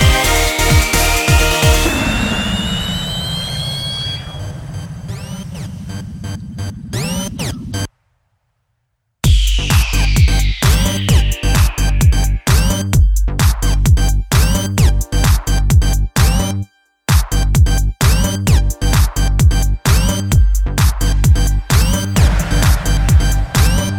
For Solo Male R'n'B / Hip Hop 4:22 Buy £1.50